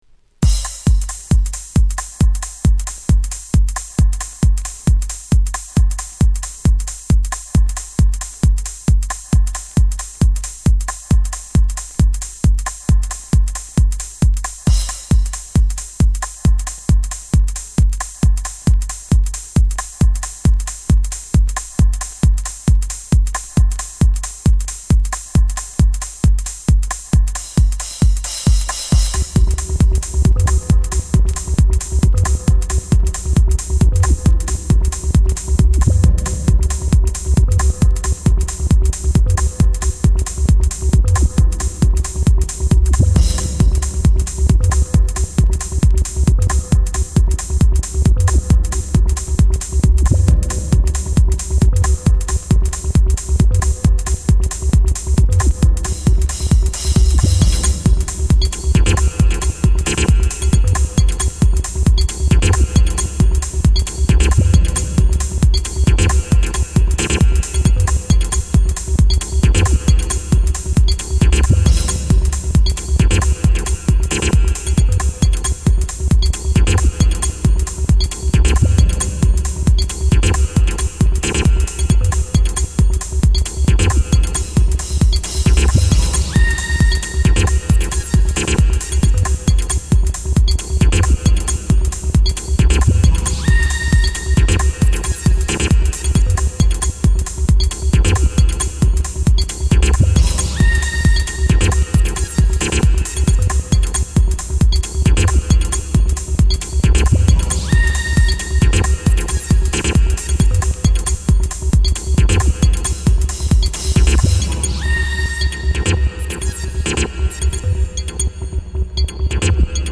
Early House / 90's Techno